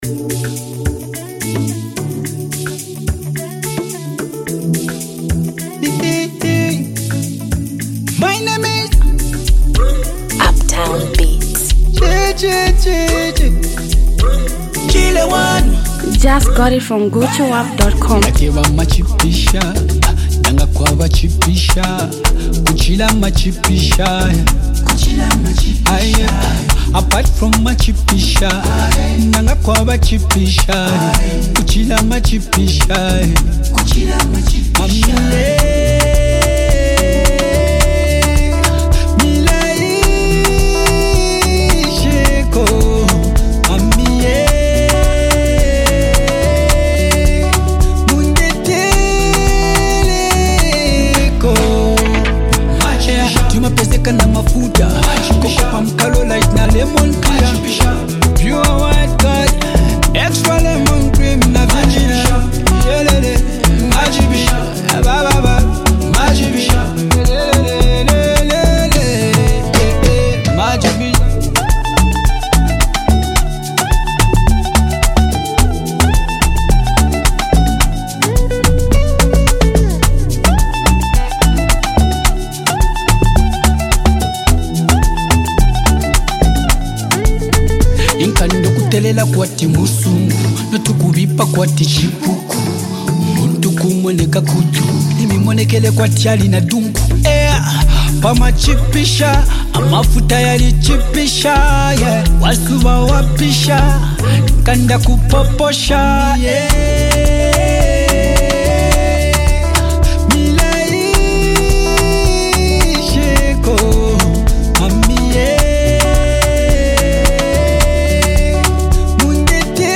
a high-octane track produced by the king of dancehall
infectious beat and smooth vocals